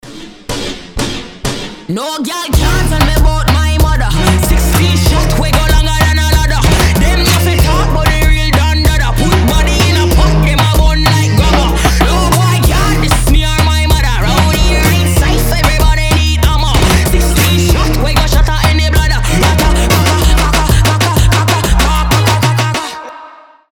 • Качество: 320, Stereo
ритмичные
выстрел
pop rap